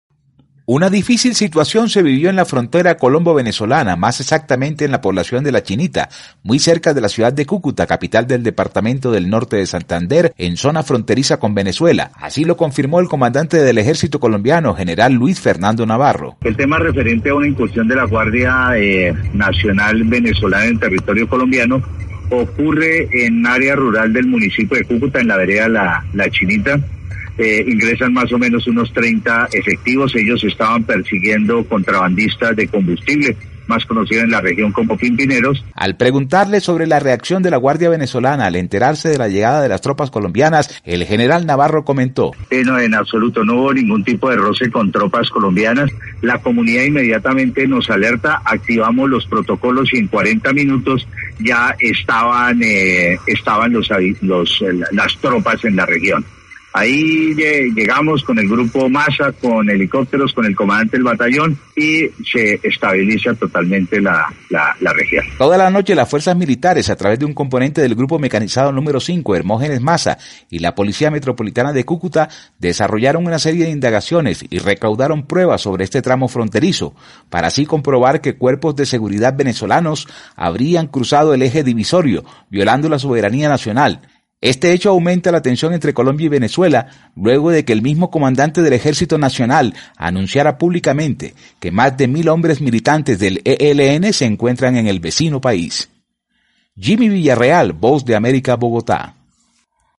VOA: Informe desde Colombia